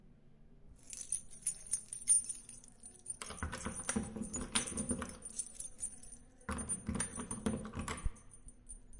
锁匙关闭
标签： 按键 开锁 钥匙
声道立体声